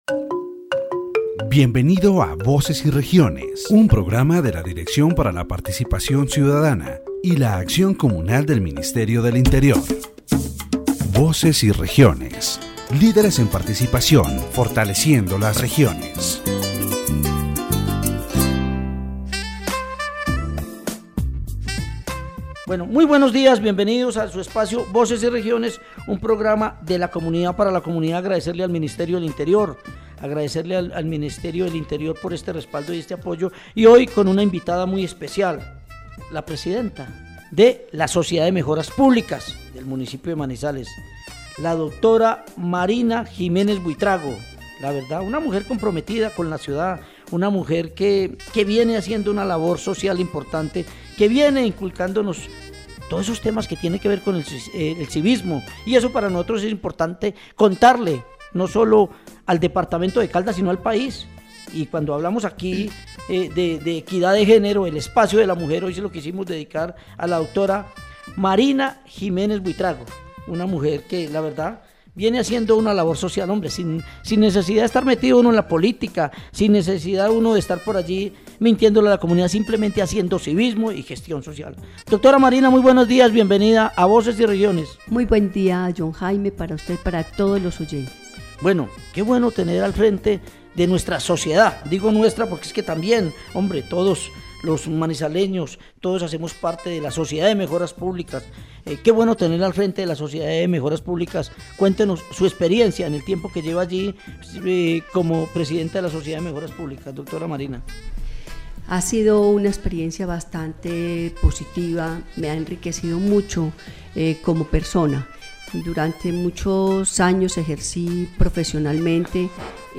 is interviewed.